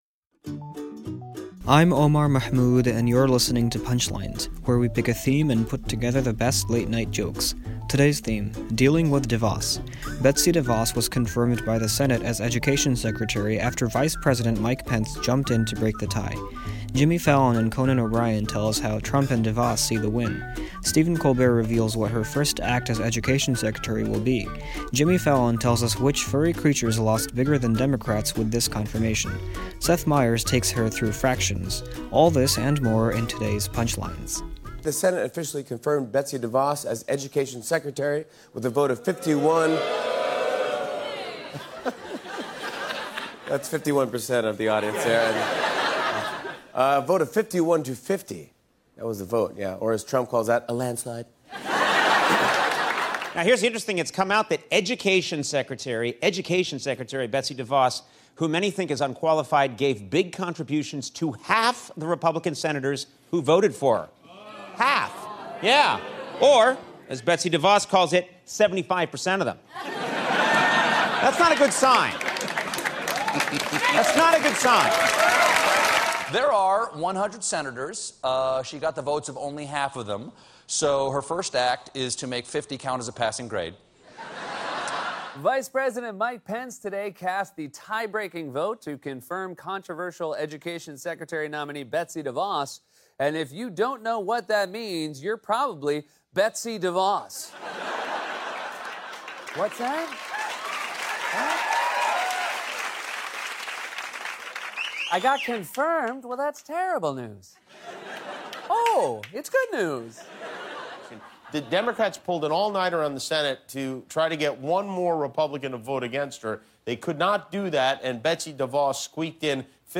The late-night comics on the confirmation of Betsy DeVos.